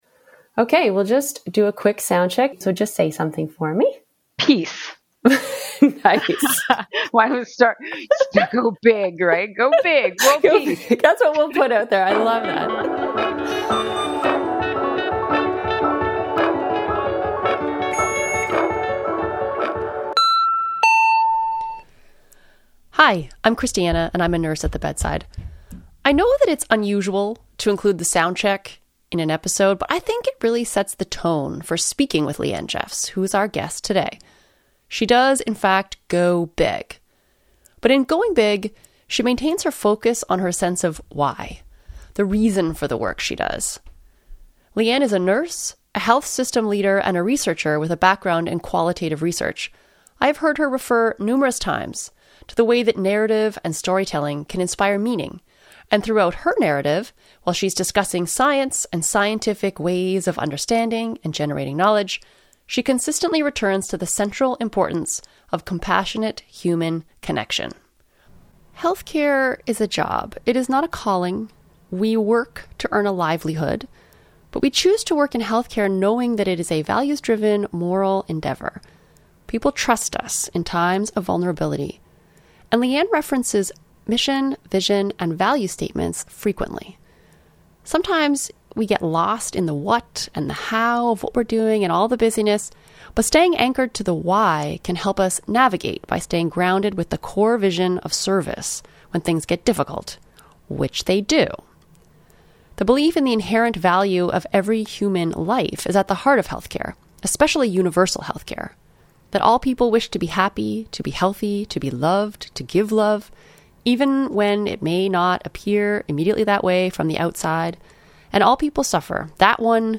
Driven by curiosity, informed by evidence, and rooted in nursing practice. Conversations on health care.